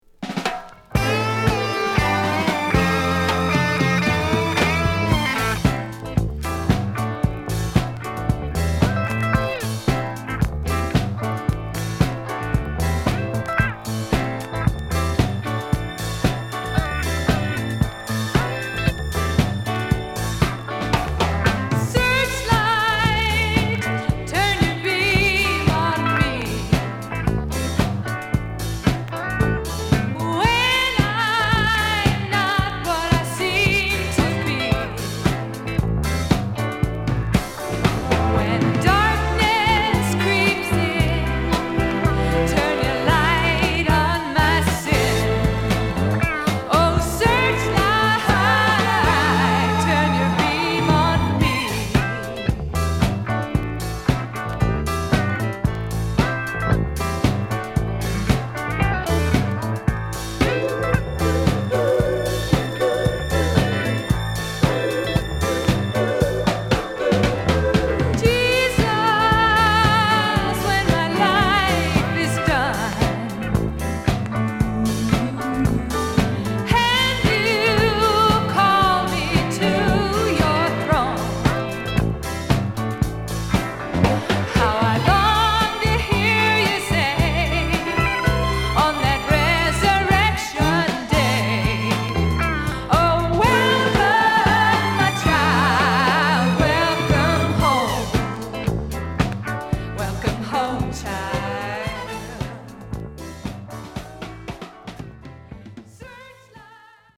Contemporary Christian Music
途中フォーキーロック的な展開もありますが、それも含めてのレアグルーヴ・ブギー45s！